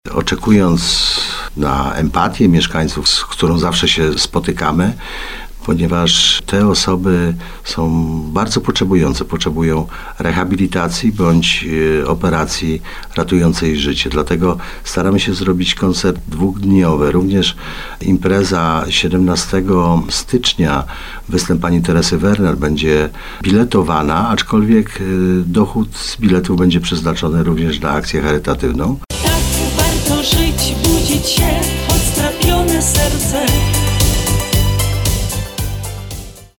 Dochód zostanie przeznaczony na leczenie i rehabilitację trójki mieszkańców gminy Pilzno – zapowiada burmistrz Pilzna Tadeusz Pieczonka.